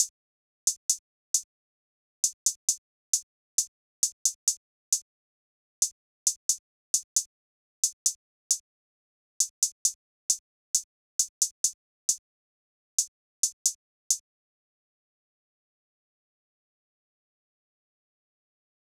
drill (!)_BWB WAV R US HAT (2).wav